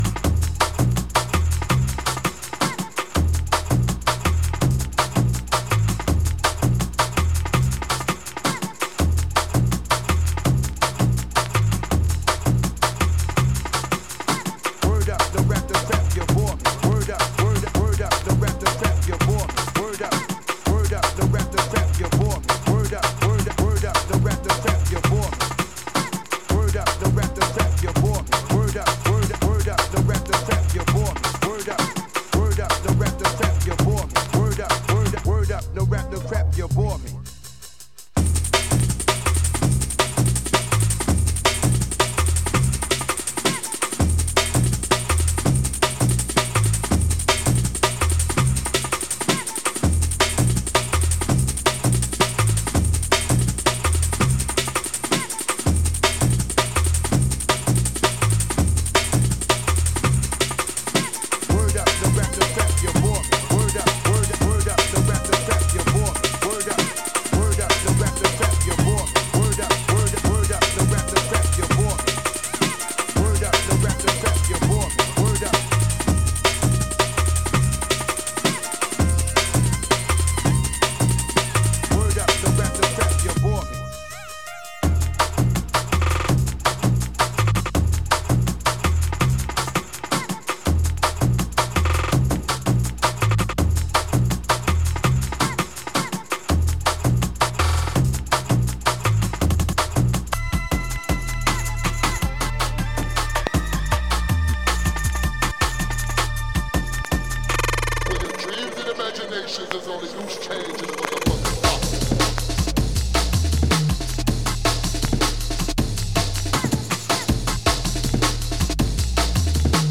Light marks and scratches cause some light noise on playback